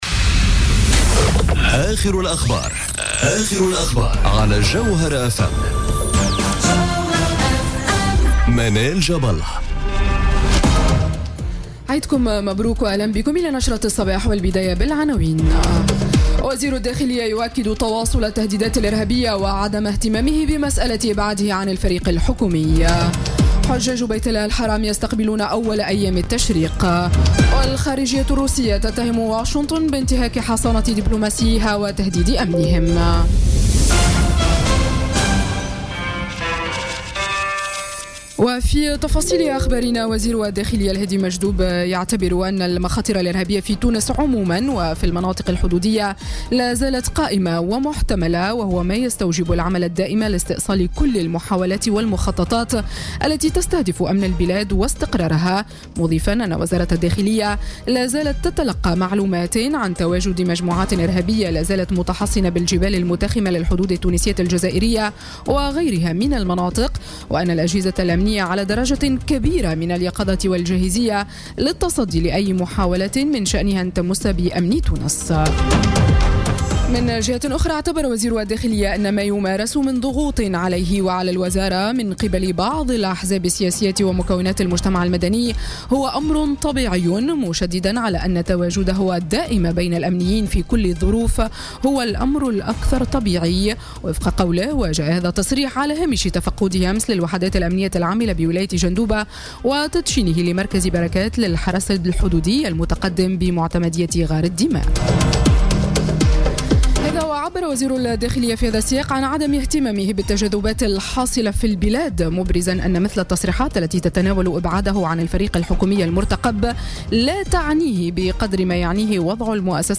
نشرة أخبار السابعة صباحا ليوم السبت 2 سبتمبر 2017